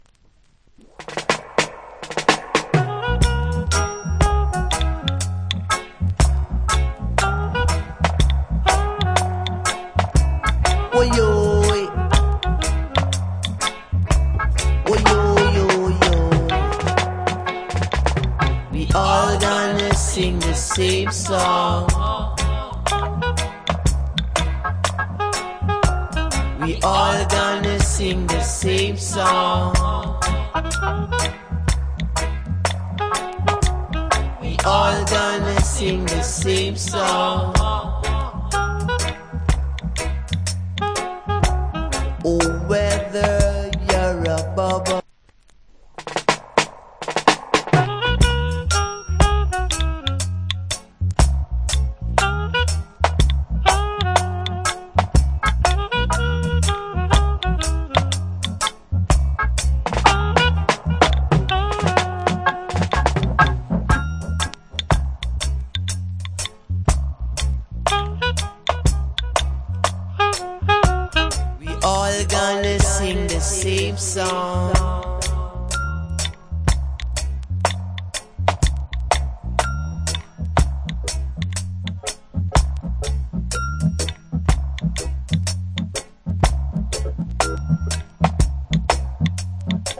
Roots Foundation.